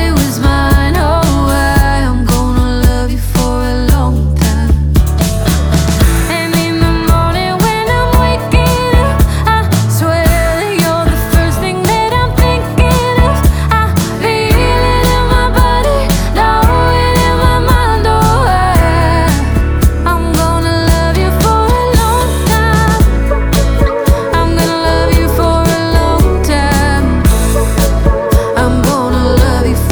Genre: Alternative